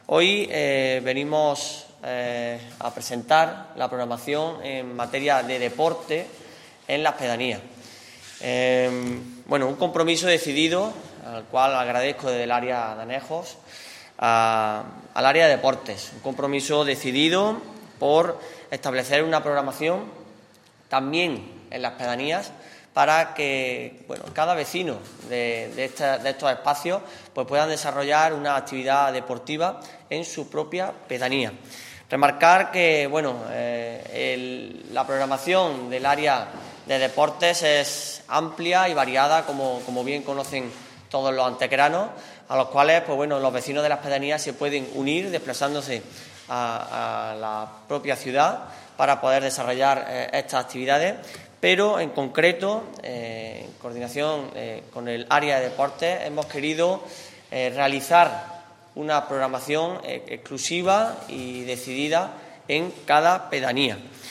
El concejal delegado de Anejos y Desarrollo Rural, José Manuel Fernández, ha presentado hoy en rueda de prensa la nueva programación deportiva en las pedanías de Antequera, que tendrá carácter permanente hasta el próximo verano y se desarrollará semanalmente a partir del próximo lunes 2 de octubre.
Cortes de voz